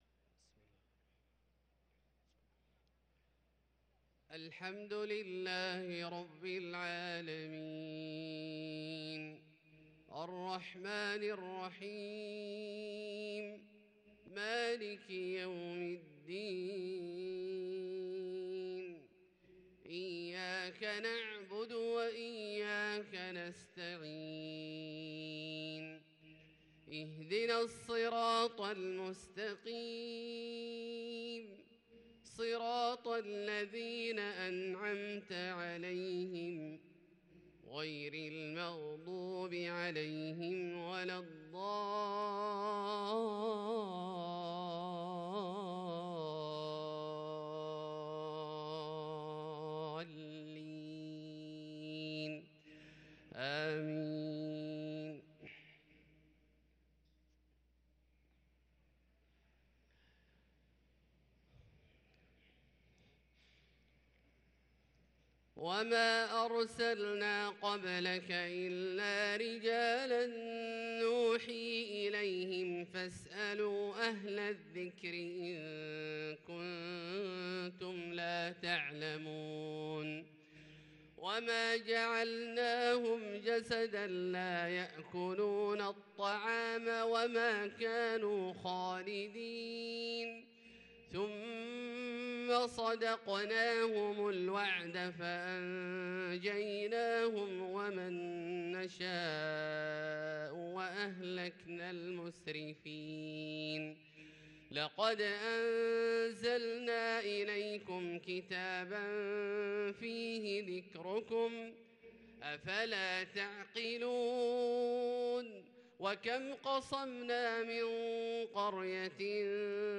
صلاة الفجر للقارئ عبدالله الجهني 30 جمادي الأول 1444 هـ